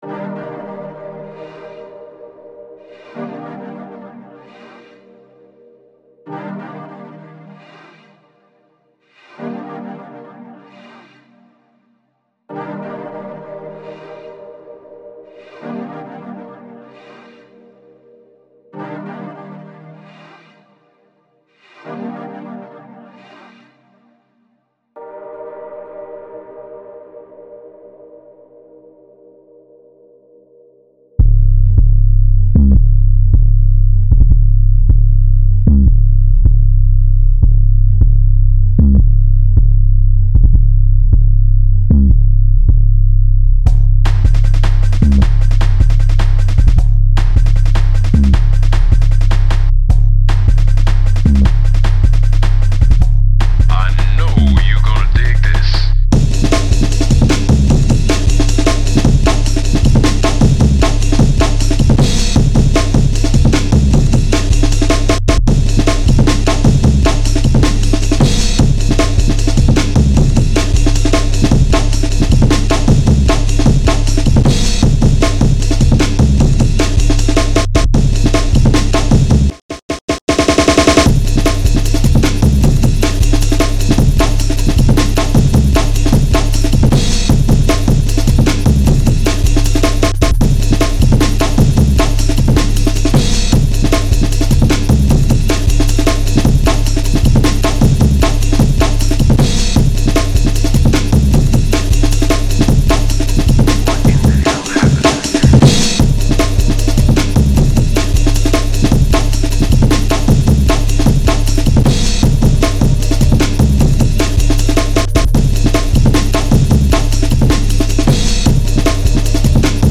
my honest first attempt at jungle music (or something of similar matter)
Techno